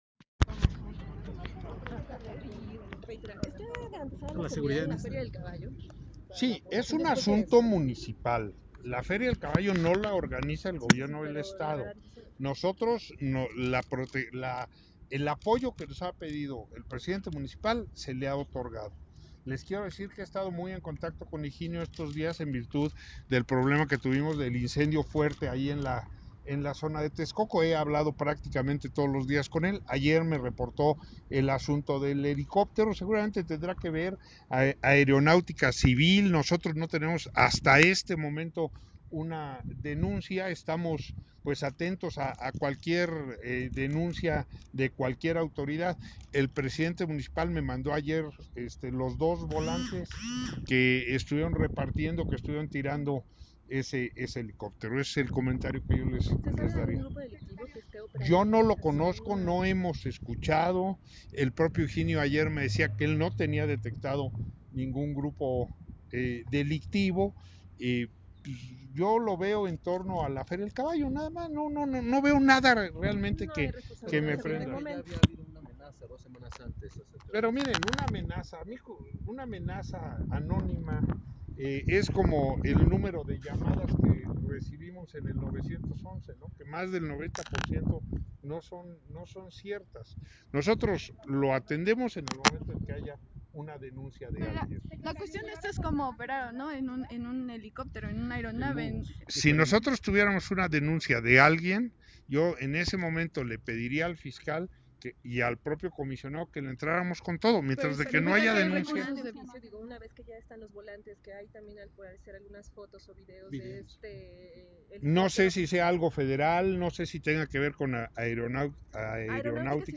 Los mensajes lanzados fueron firmados por una célula como La Familia Unida, el secretario general de Gobierno, José Manzur Quiroga, aseguró sería un tema entre dos bandas delictivas, descartando que sea un asunto electoral.
NARCO-VOLANTES-EN-LA-FERIA-DE-TEXCOCO-DECLARACIÓN-JOSÉ-MANZUR-QUIROGA.m4a